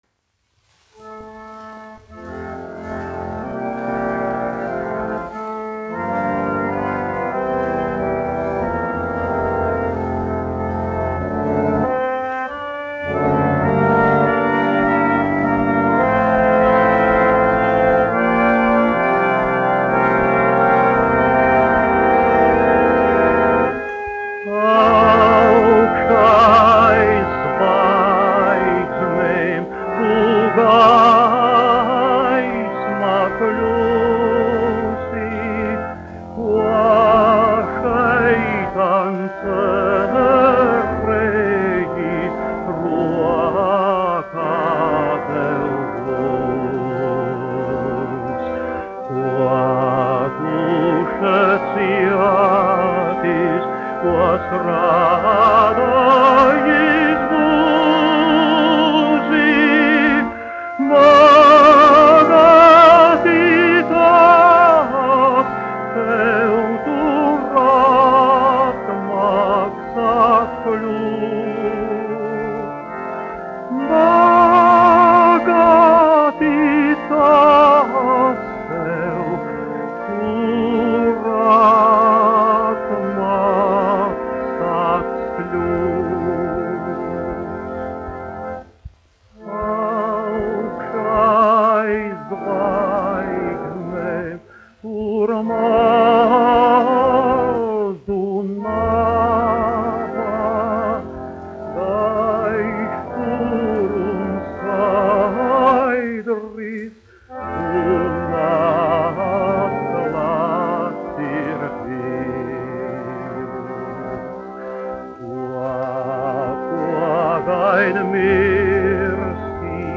Mariss Vētra, 1901-1965, dziedātājs
1 skpl. : analogs, 78 apgr/min, mono ; 25 cm
Garīgās dziesmas ar ērģelēm
Latvijas vēsturiskie šellaka skaņuplašu ieraksti (Kolekcija)